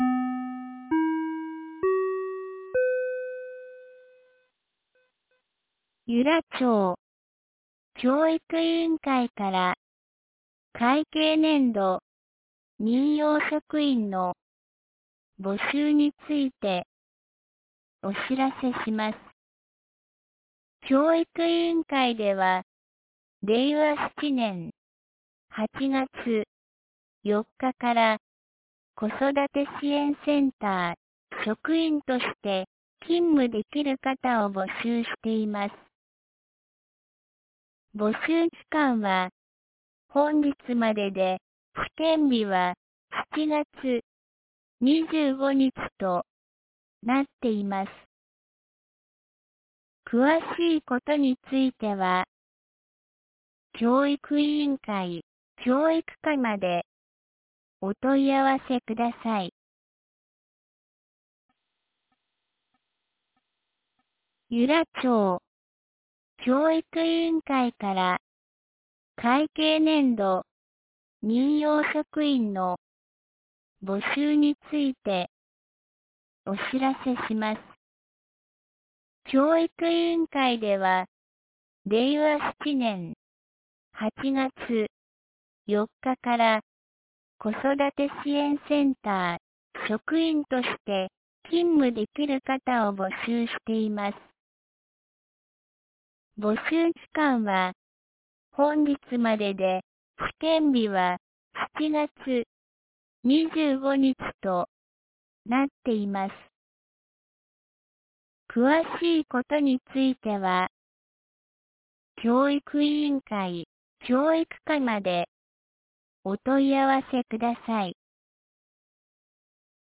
2025年07月22日 07時52分に、由良町から全地区へ放送がありました。